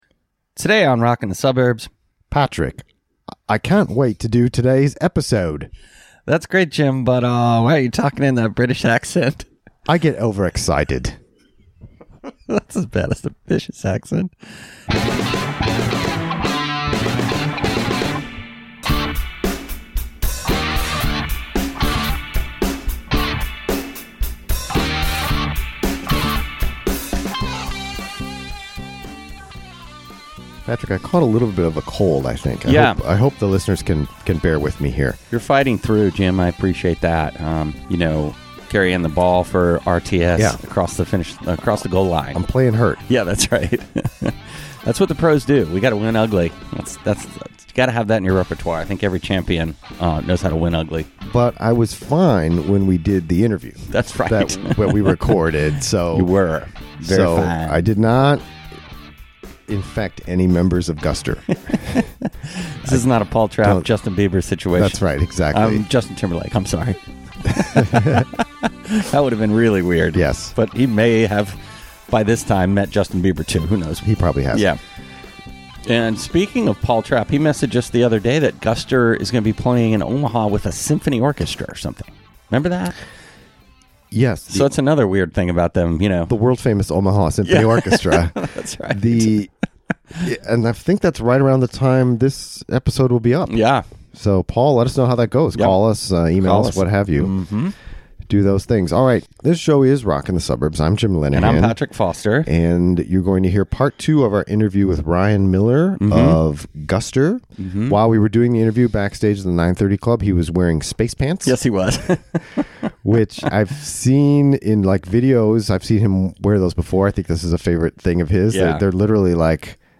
Part 2 of our interview with Ryan Miller of Guster, backstage at the 9:30 Club in Washington DC. He talks about one of their more ambitious new songs and the band's relationship with its fans.